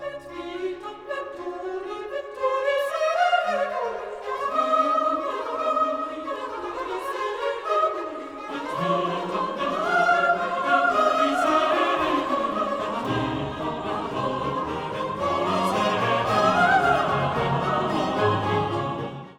“No. 12”, completed just before Heinichen died of tuberculosis, is lyrical, accessible, and folklike throughout.  It has playful settings of “Quoniam” and “
Et vitam venturi saeculi”—a musical style in church music as far as I can tell unknown among the Italians.